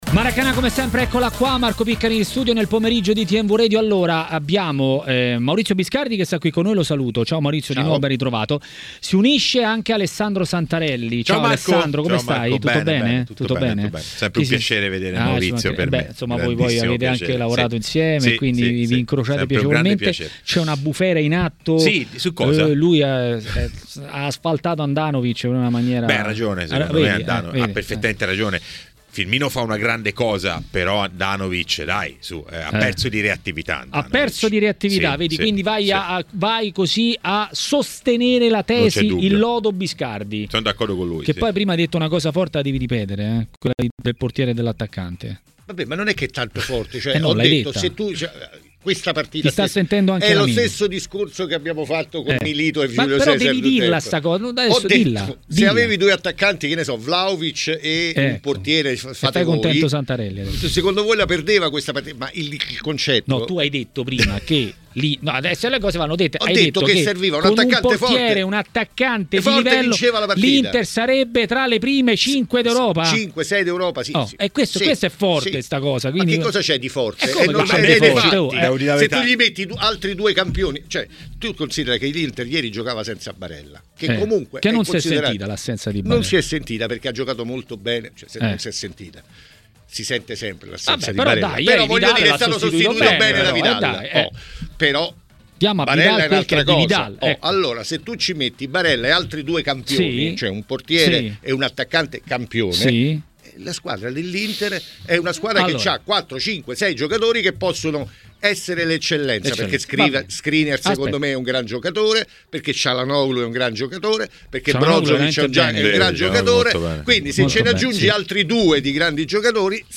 L'ex centrocampista tra le altre di Lazio, Inter e Juventus Hernanes ha parlato a TMW Radio, durante Maracanà, dei temi di maggior attualità.